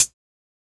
Perc (14).wav